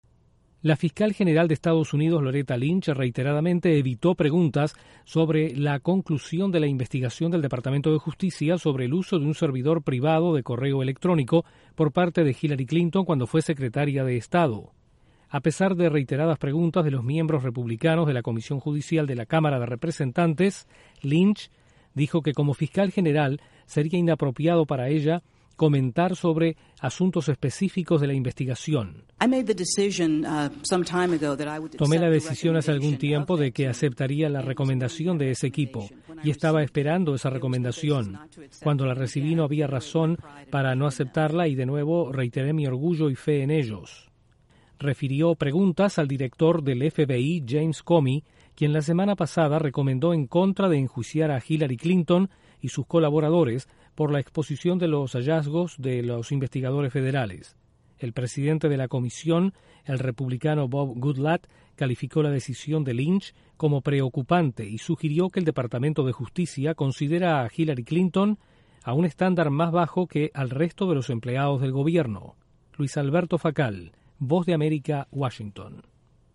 La fiscal general de Estados Unidos, Loretta Lynch, desvió preguntas del Congreso sobre el caso de Hillary Clinton. Desde la Voz de América en Washington informa